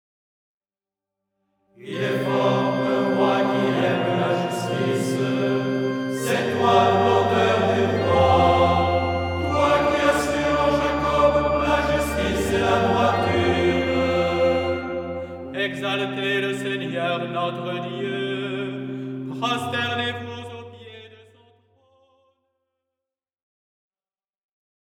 Hymnes & Tropaires
Format :MP3 256Kbps Stéréo